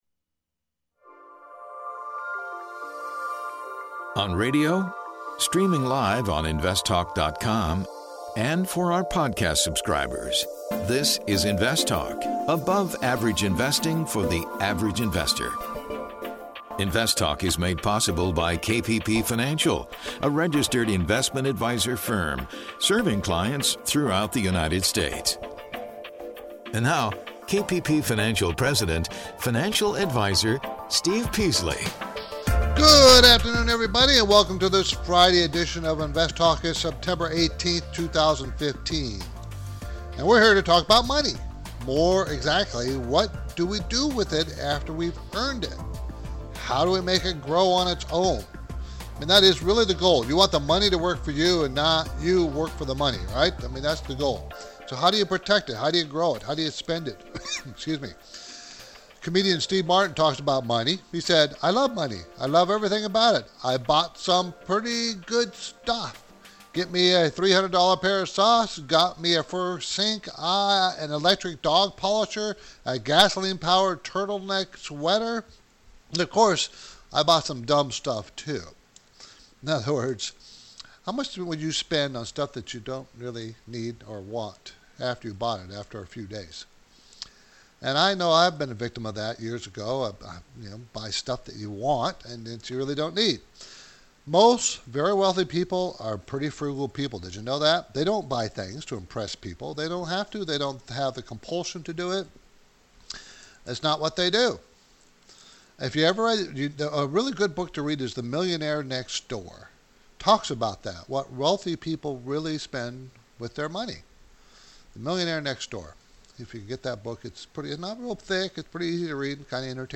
An industry expert tells about current and upcoming developments in the fast moving ETF marketplace.